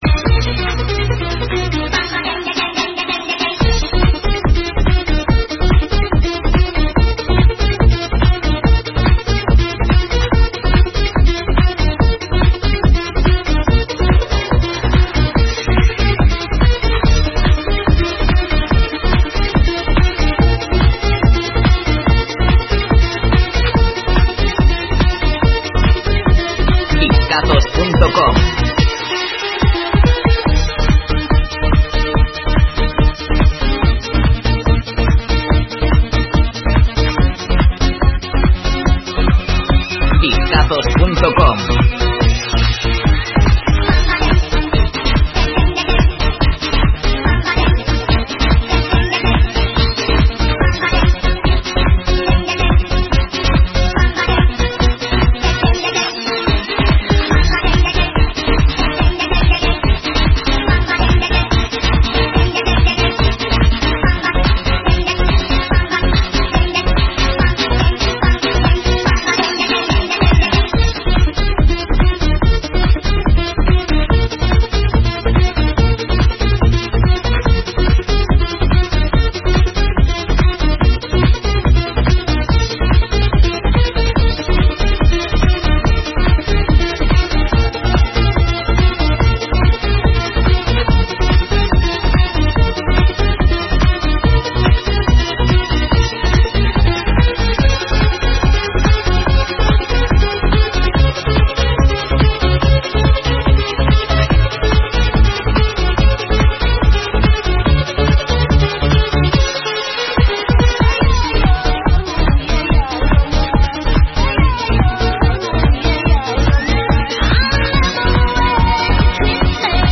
TODO UN CLÁSICO DE LOS 90,EDICIÓN IMPORT¡